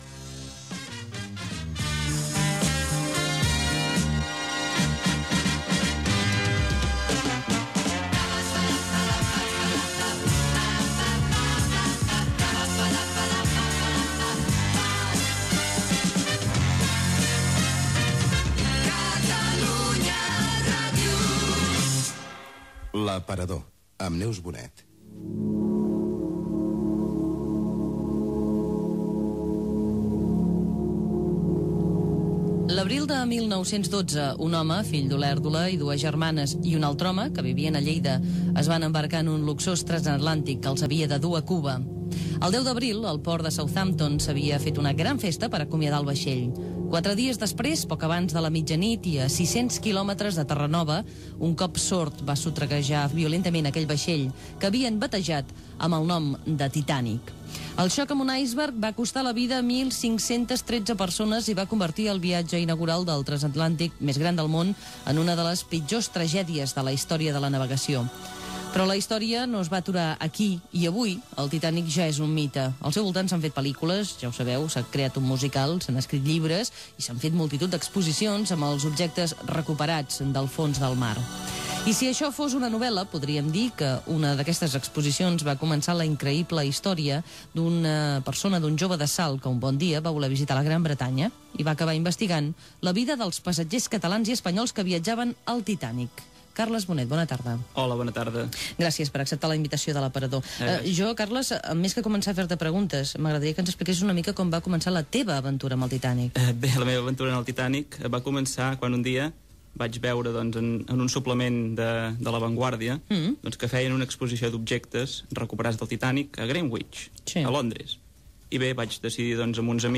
Sintonia de l'emissora, careta del programa.
Entreteniment